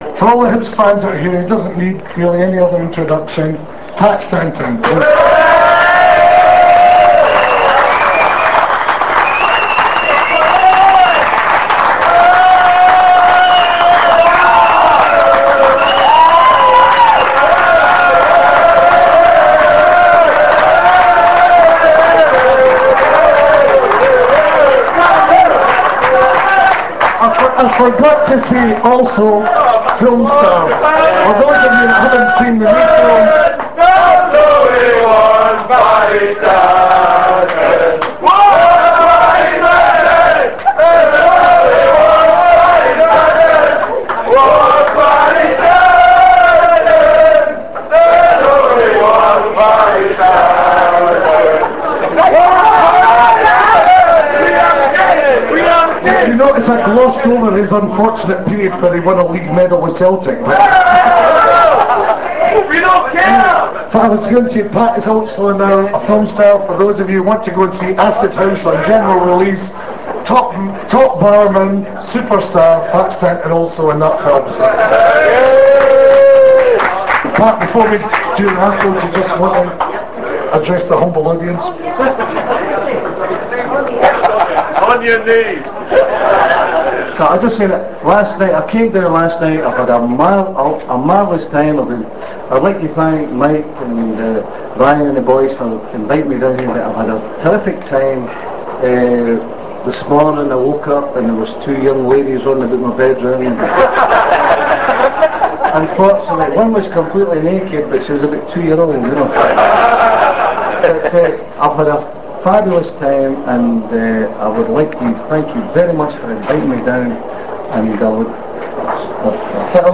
London Hibs Annual Burns Night Supper was held on Saturday 30th January 1999 at the Holyrood Pub, Wells Street, off Oxford Street, and a good time was had by one and all.
We were honoured with the presence of one of the most famous Hibs players from the past, Pat Stanton, who said a few words,
pat_stanton_speech.rm